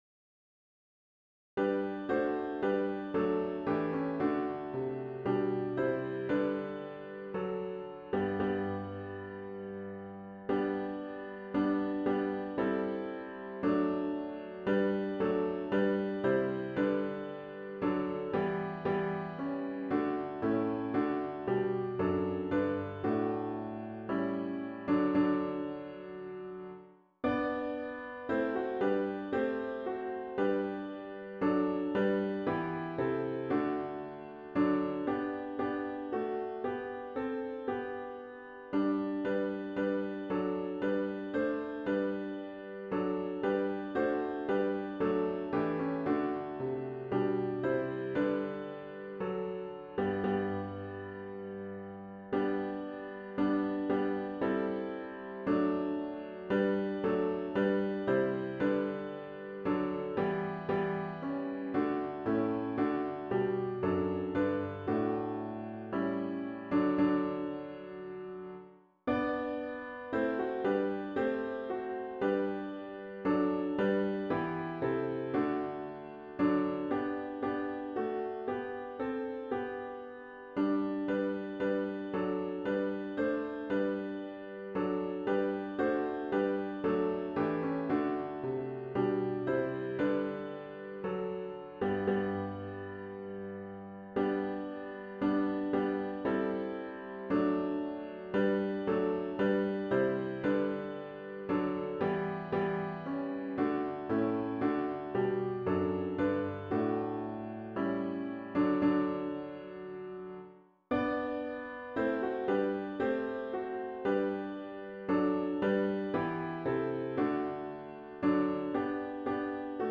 *OPENING HYMN “O Come, All Ye Faithful” GtG 133 (Verses 1, 3, & 4)